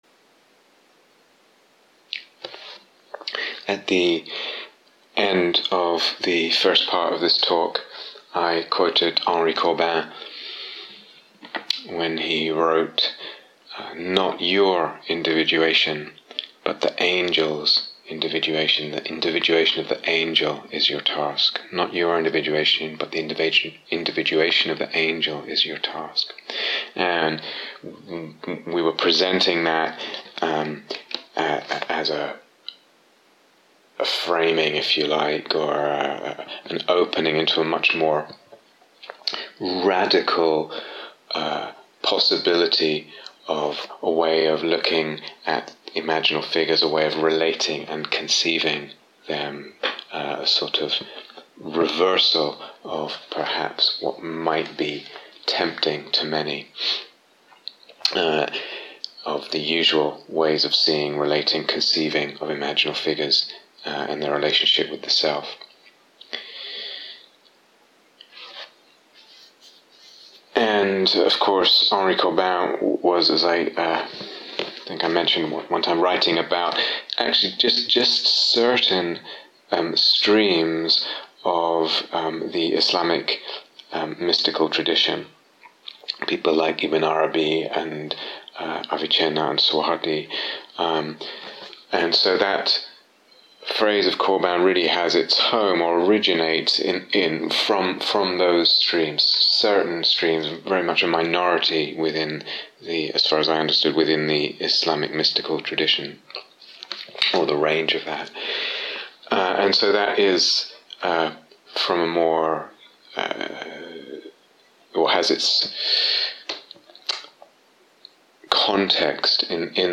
The Love and Demands of the Imaginal (Part 2) The set of talks and meditations from this course outlines the foundations and some of the possibilities for opening up a practice of the imaginal.